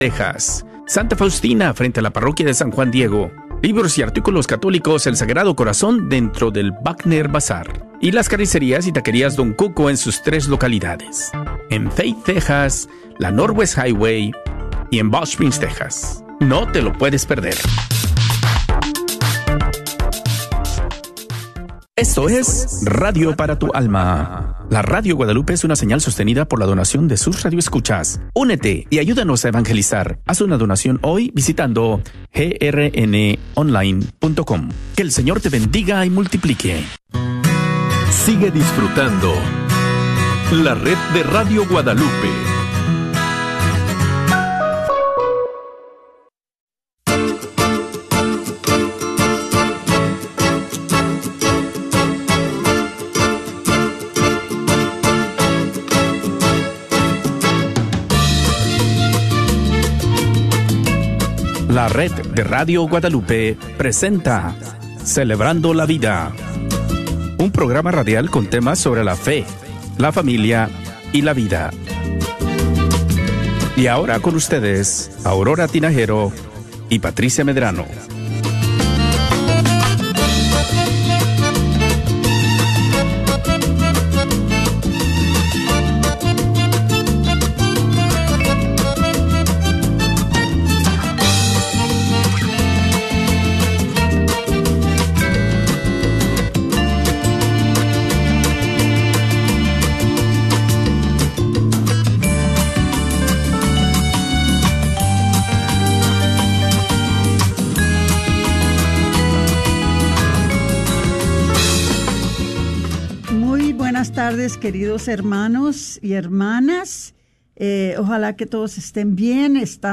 Este programa fue emitido por primera vez el Martes 13 de mayo